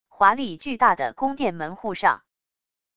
华丽巨大的宫殿门户上wav音频